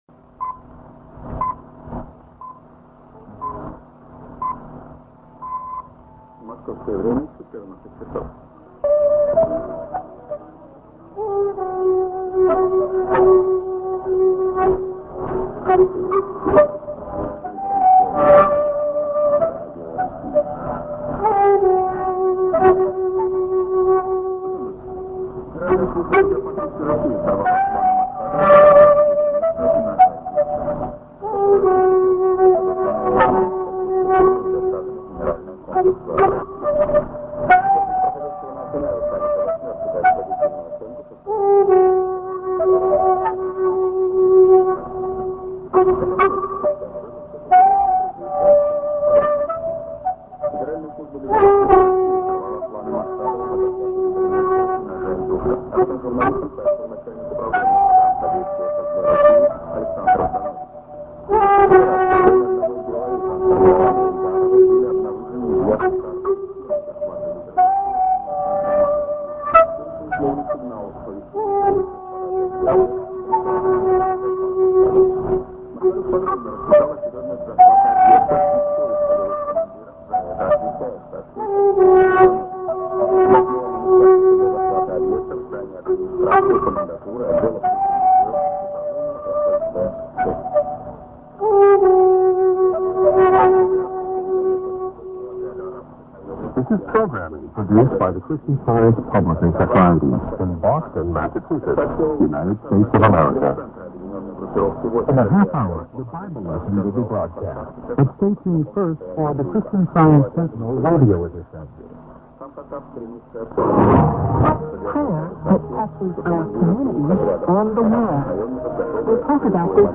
St. TS->IS->ID(man)->prog-> QRM | Via Taipei